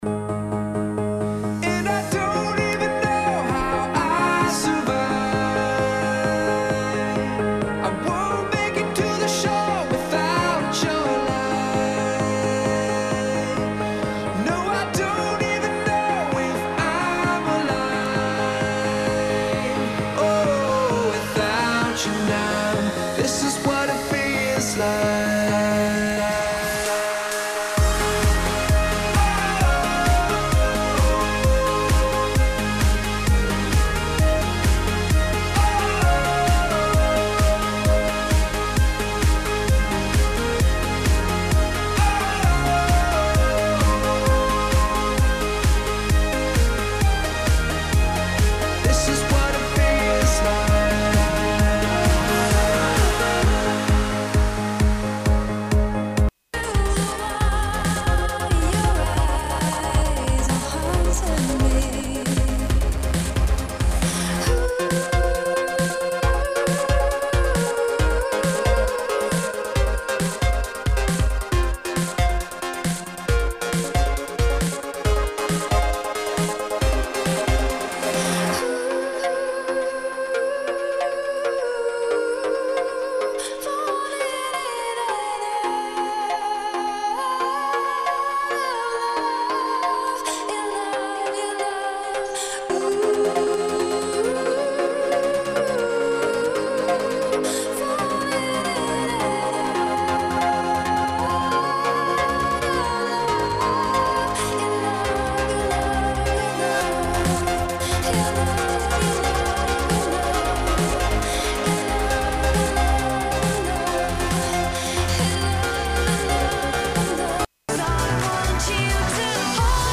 House-Trance